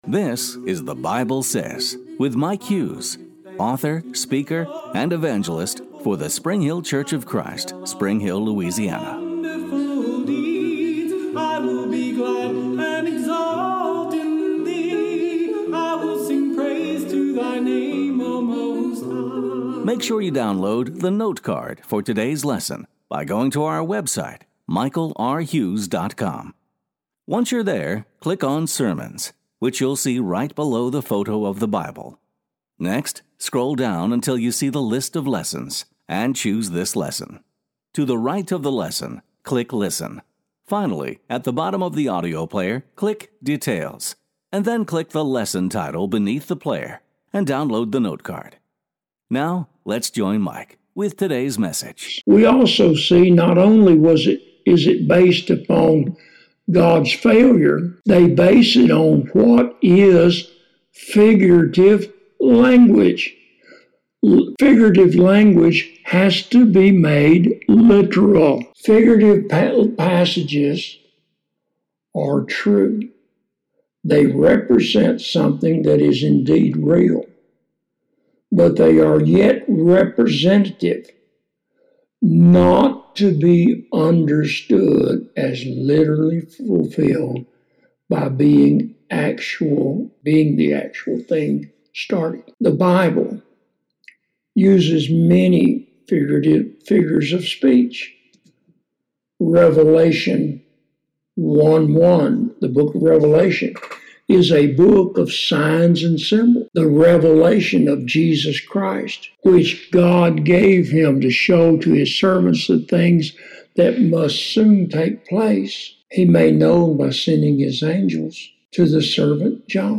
Sunday Afternoon